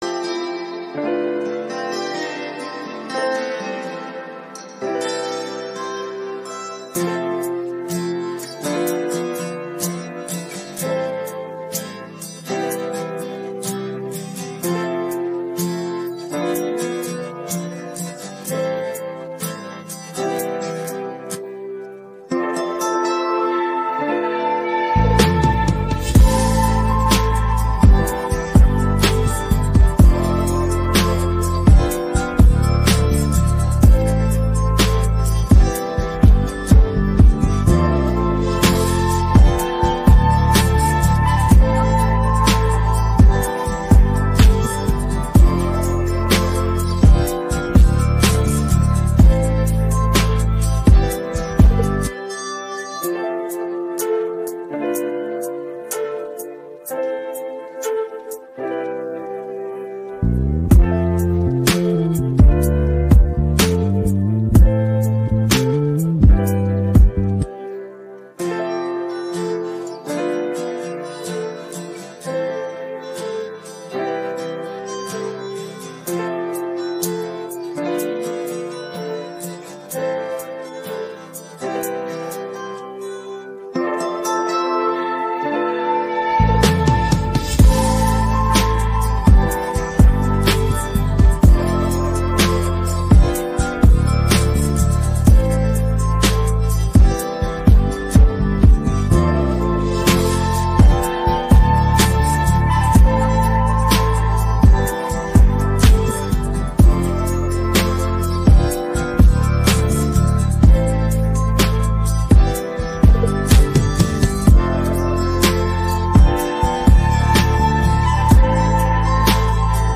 українське караоке 622